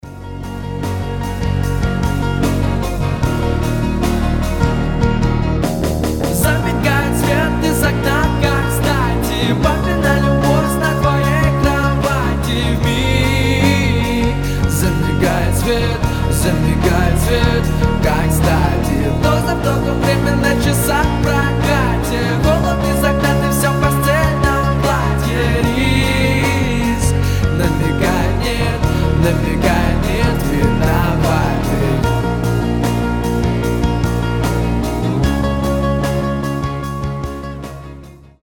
• Качество: 320, Stereo
гитара
атмосферные
пост-рок